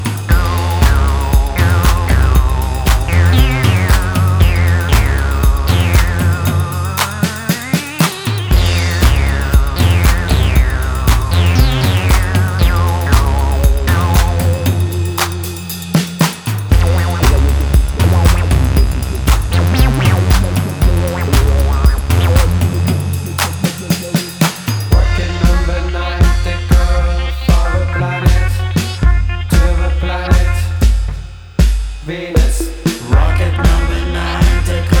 Жанр: Поп музыка / Альтернатива / Электроника
Alternative, French Pop, Electronic